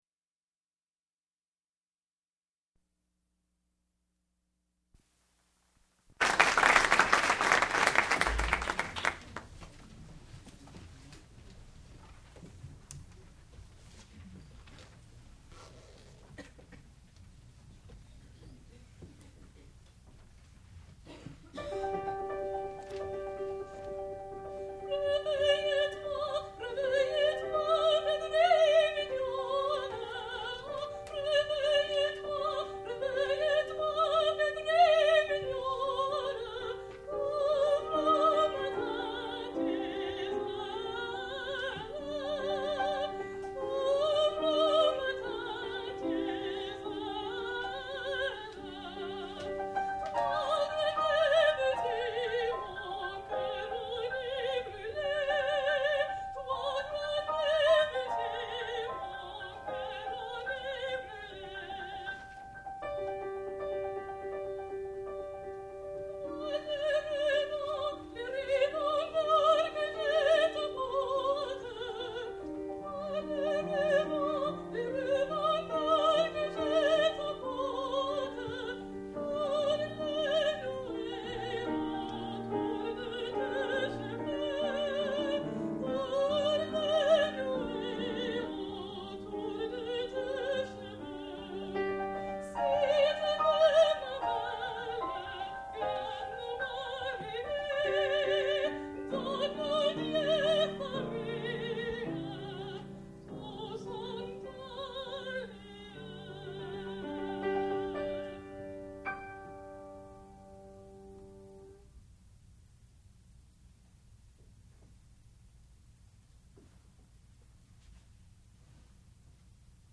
sound recording-musical
classical music
piano
Master's Recital
mezzo-soprano